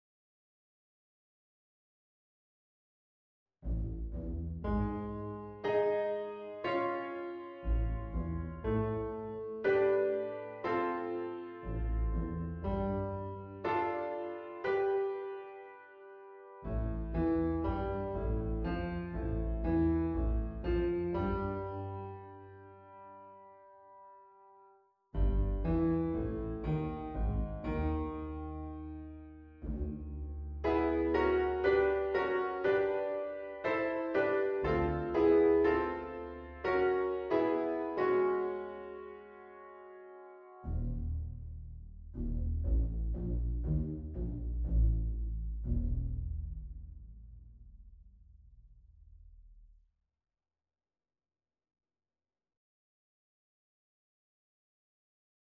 • In bar 9 and 10, the time signature alters between 4/4 and 2/4.
After the intro, the piece starts in D-Dorian Mode, shifts to Eb-Dorian and goes back to D-Dorian at the end.